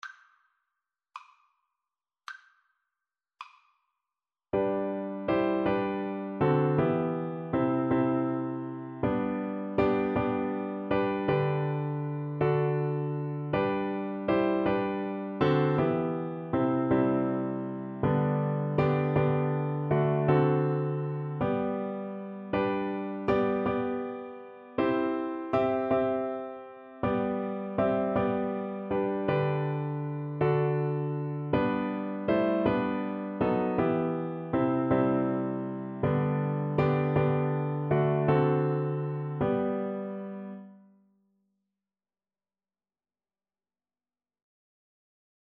• Unlimited playalong tracks
6/4 (View more 6/4 Music)